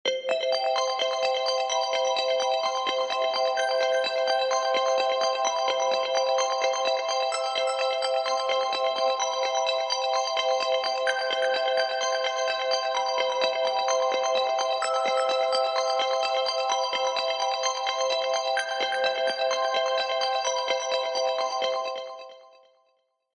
macOSsystemsounds